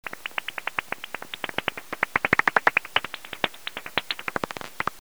Pipistrelles usually sound like irregular “smacks” that tend to vary in pitch and are at a medium repetition rate.
These are pipistrelle calls as heard on a heterodyne bat detector.
Soprano pipistrelle –